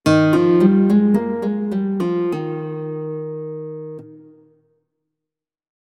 -Selectie Super Art. Concert Guitar
-Initial Touch uitgeschakeld (dus volume is niet afhankelijk van hoe hard/snel de toets wordt ingedrukt)
Wanneer er legato wordt gespeeld, hoor je het probleem dat jij omschrijft.
Legato.mp3